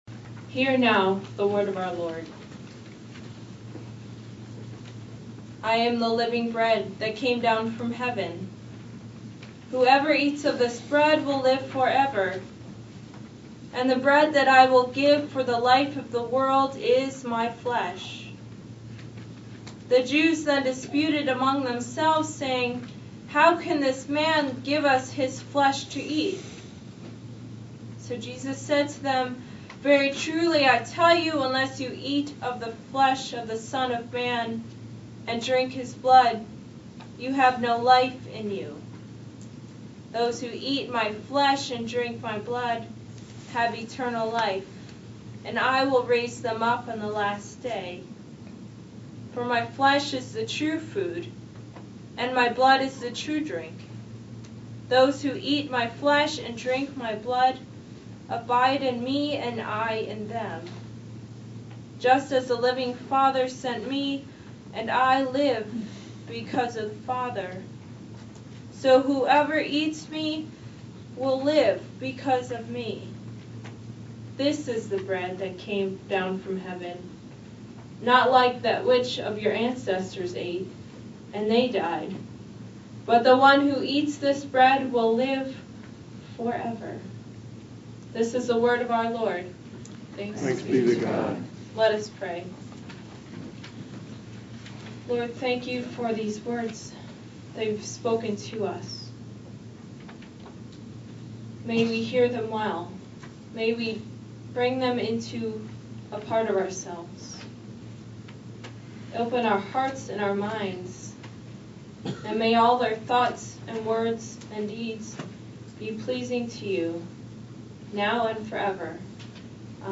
Sermons and Anthems | The Second Reformed Church of Hackensack